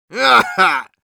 Medic_laughshort02_ru.wav